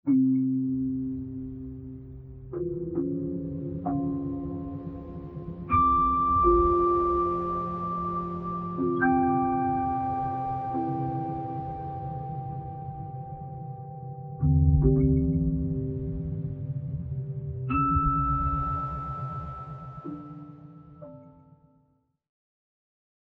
Figure 4. Analysis with smoothed frequency over 10 frames
Ten frames for smoothing is not a lot of change; one can hear subtle flutters on the low and low-mid frequencies.